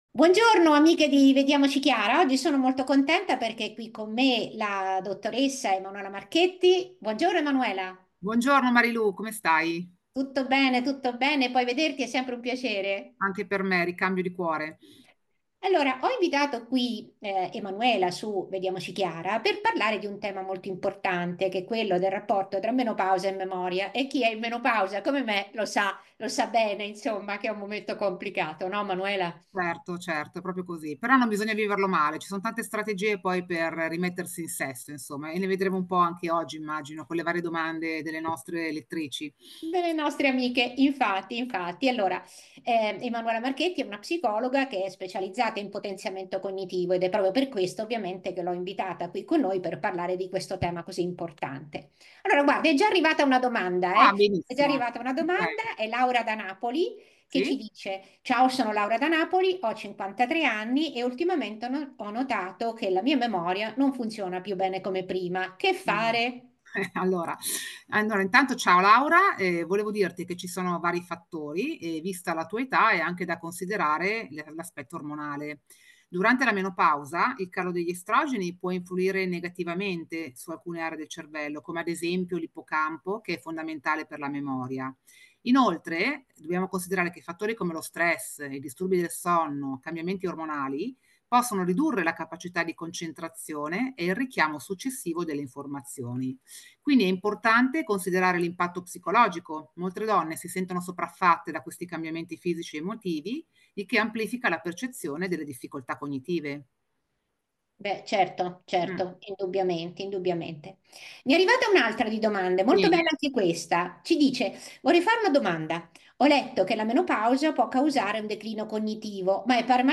Un evento molto partecipato e divertente, dedicato a chi di noi in menopausa ha problemi di memoria e si chiede se la nebbia in menopausa si può curare Menopausa e Memoria: Ho 53 anni e ultimamente ho notato che la mia memoria non funziona più bene come prima.